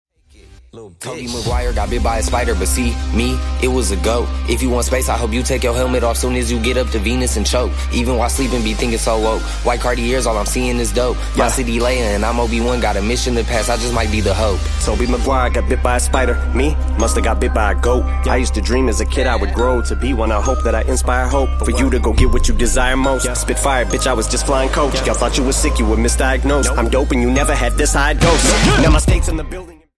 Hip-Hop / Urban